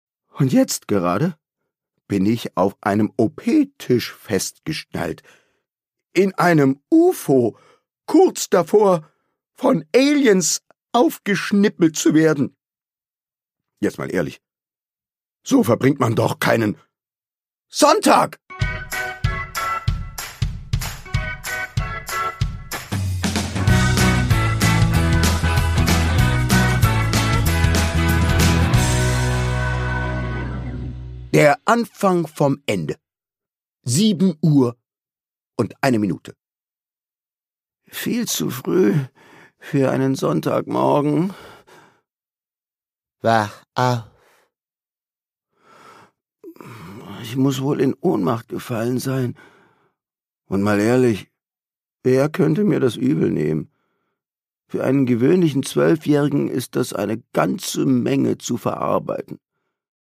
Produkttyp: Hörbuch-Download
Gelesen von: Thomas Nicolai